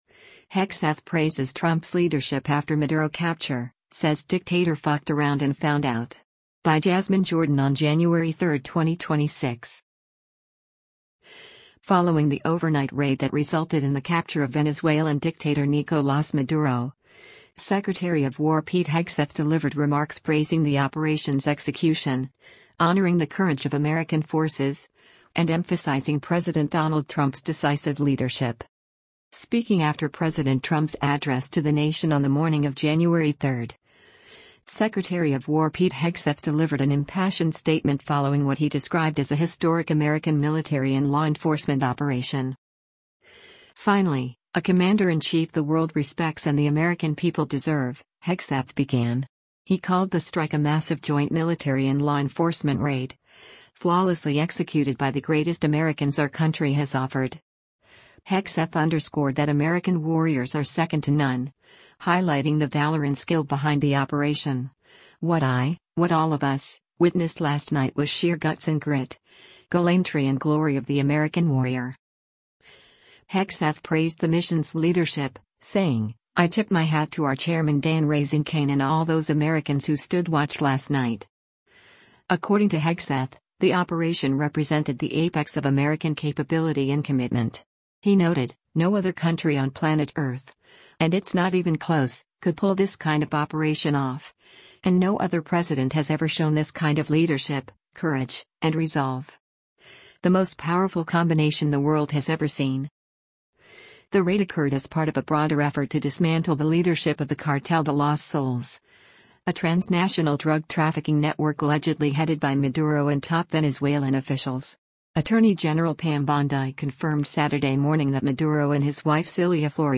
Defense Secretary Pete Hegseth speaks at Mar-a-Lago, Saturday, Jan. 3, 2026, in Palm Beach
Speaking after President Trump’s address to the nation on the morning of January 3, Secretary of War Pete Hegseth delivered an impassioned statement following what he described as a historic American military and law enforcement operation.